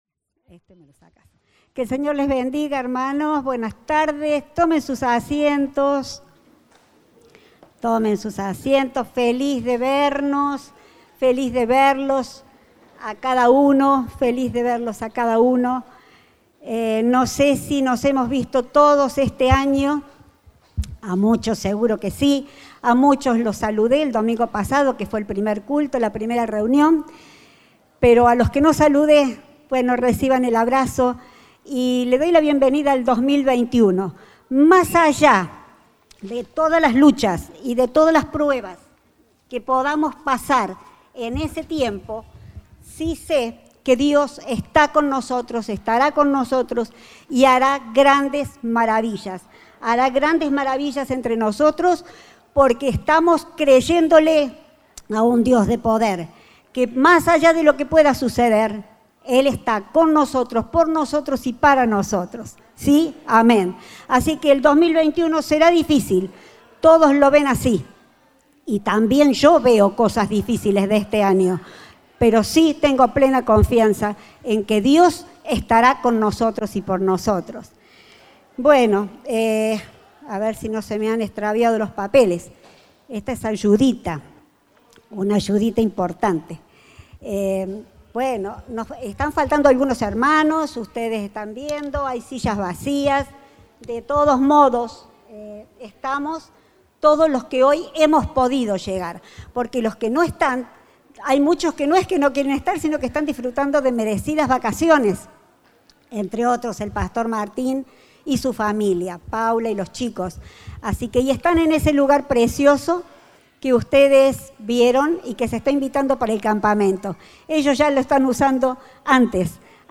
Compartimos el mensaje del Domingo 10 de Enero de 2021